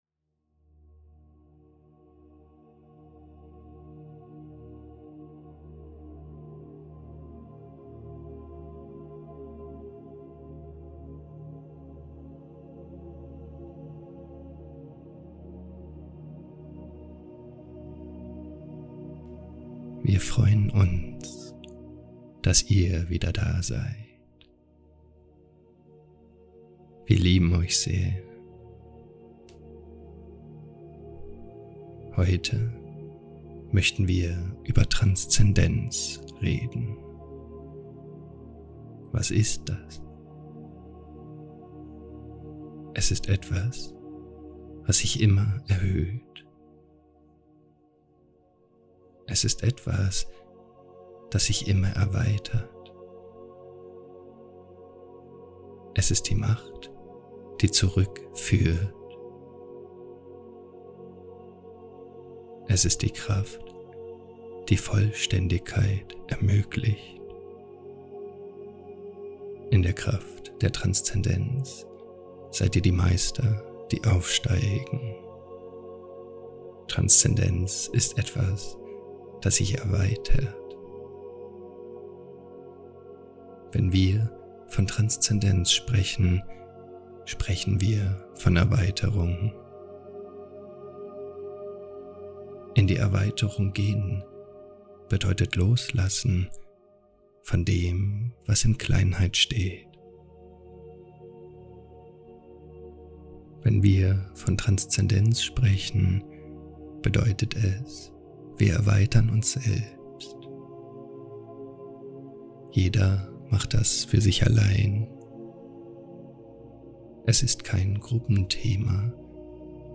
Live Fragen und Antworten an RA - Aufzeichnung des Youtube Live-StreamsDer Beitrag RAラ – LiveChanneling 🔴 – Q&A erschien zuerst auf Licht-Akademie.